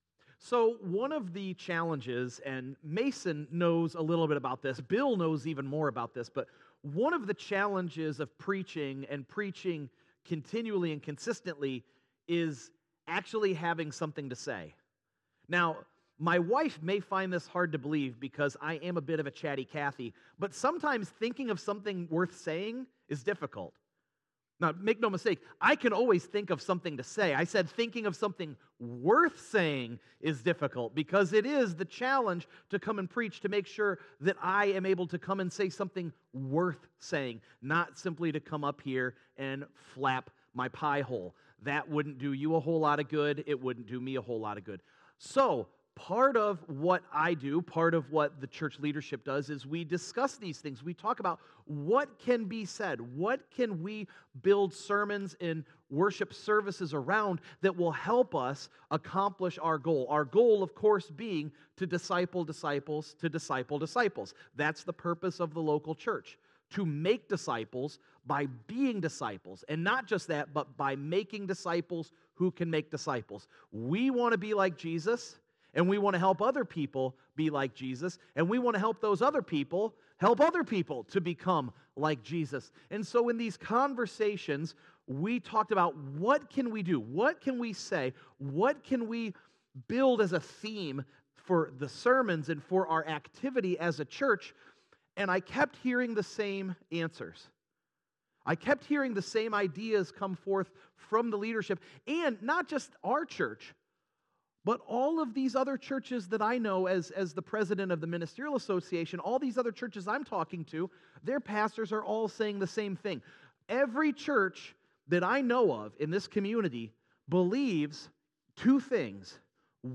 2_2_25_sunday_sermon.mp3